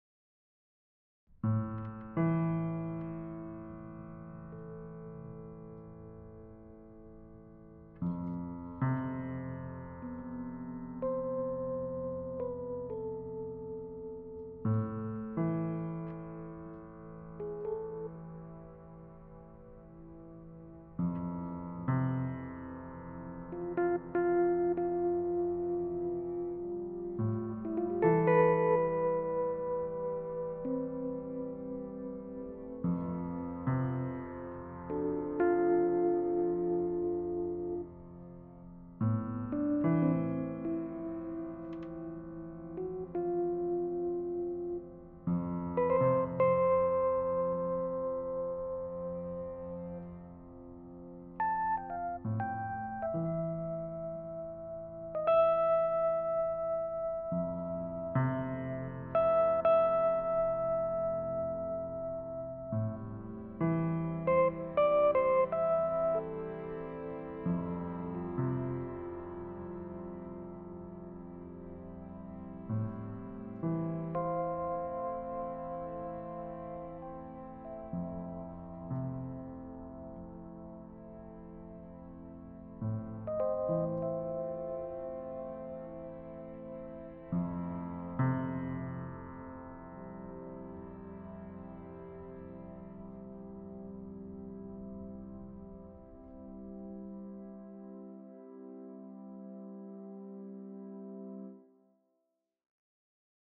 This one has some more instrumentation to it: electric piano and french horns. Both of those are sample libraries.
piano-day-4.mp3